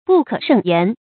不可勝言 注音： ㄅㄨˋ ㄎㄜˇ ㄕㄥˋ ㄧㄢˊ 讀音讀法： 意思解釋： 說不盡。形容非常多或到達極點。